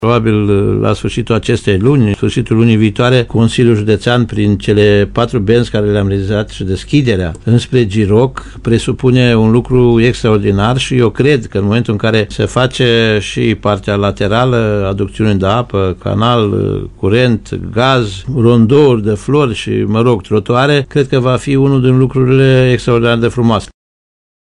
Preşedintele Consiliului Judeţean Timiş, Titu Bojin, spune că lucrările se desfăşoară pe o lungime de 2,3 km.